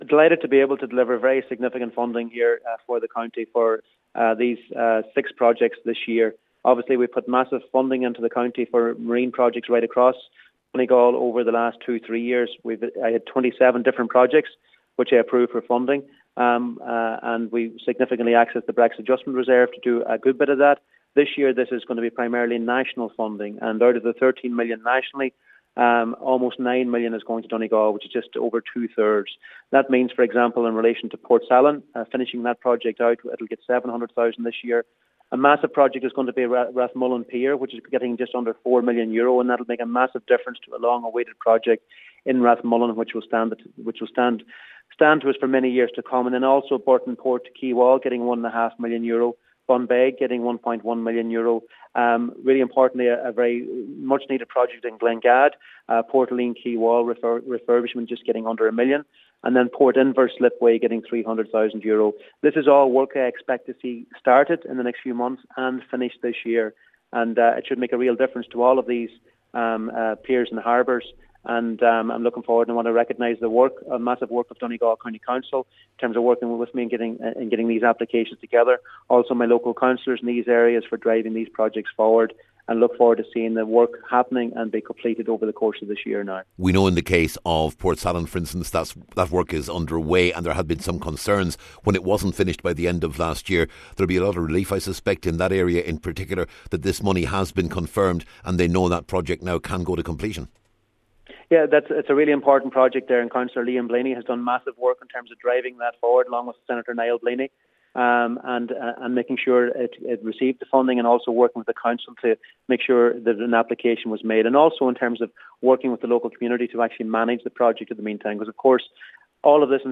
He told Highland Radio News the Donegal funding is significant………